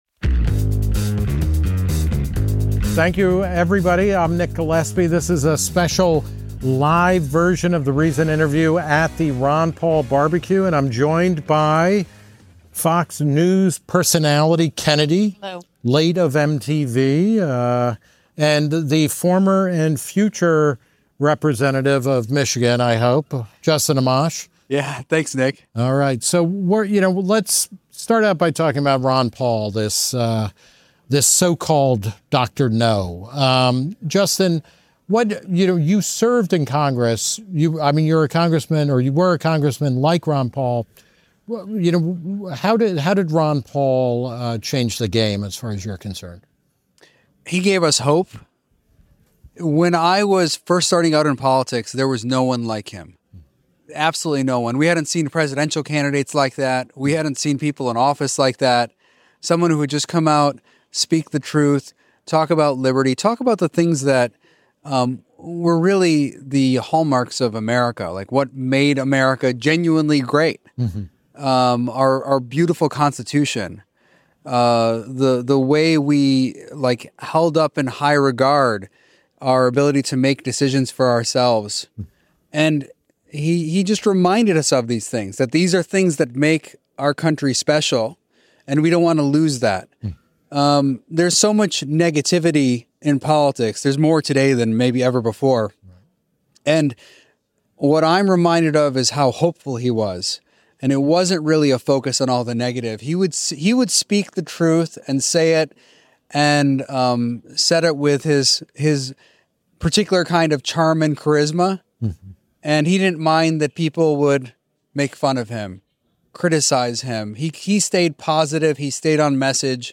Today's episode was recorded live on Saturday, August 9, at Ron Paul's 90th birthday BBQ in Lake Jackson, Texas.